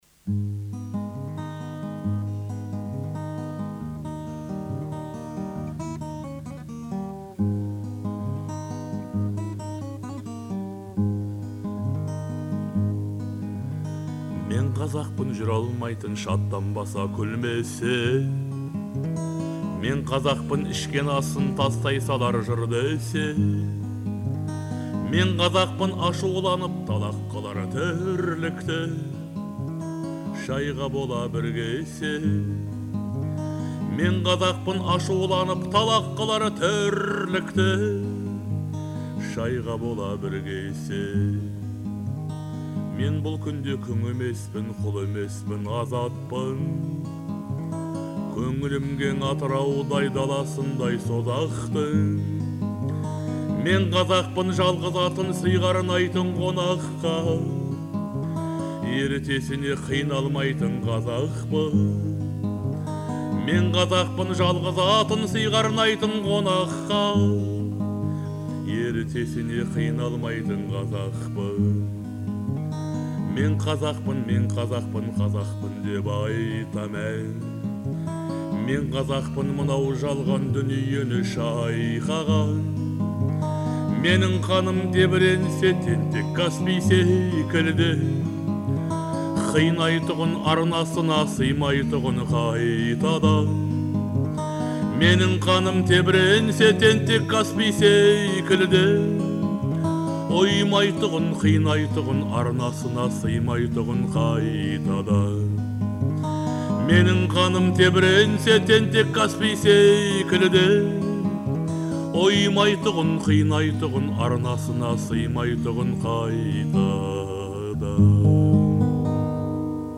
Звучание песни отличается мелодичностью и эмоциональностью